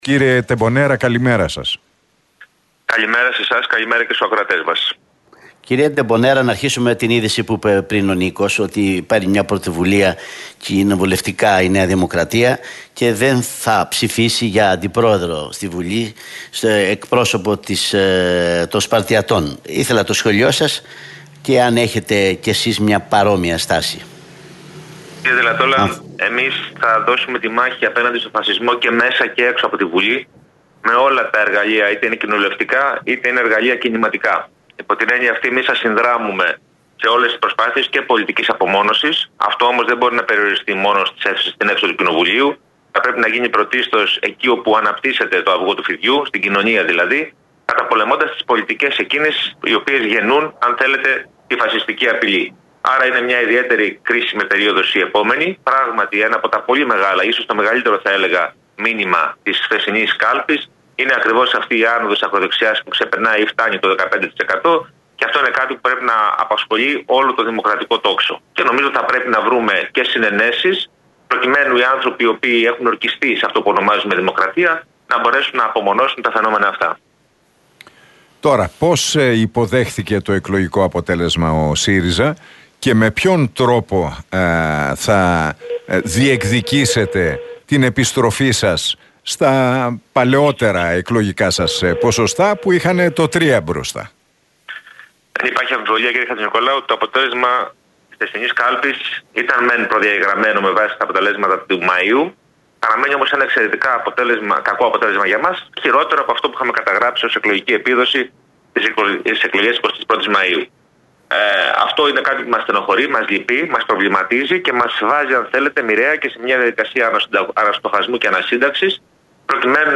Τεμπονέρας στον Realfm 97,8: Θα συνδράμουμε σε όλες τις προσπάθειες κοινοβουλευτικής απομόνωσης του φασισμού